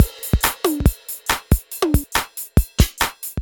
beat beats drumkit fast Gabber hardcore House Jungle sound effect free sound royalty free Music